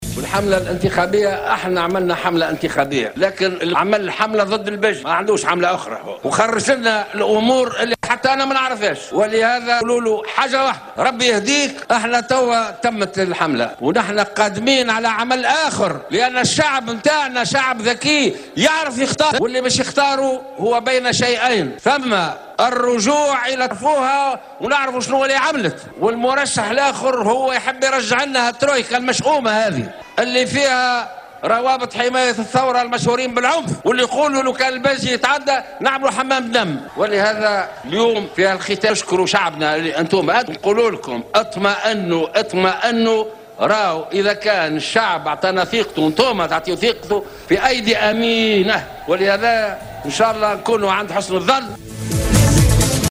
طمأن المترشح للدور الثاني من الانتخابات الرئاسية الباجي قائد السبسي في اختتام حملته الانتخابية اليوم الجمعة في شارع الحبيب بورقيبة بالعاصمة أنصاره في صورة فوزه في الانتخابات قائلا " اطمئنوا أنتم في أياد أمينة إذا ما منحتمونا ثقتكم".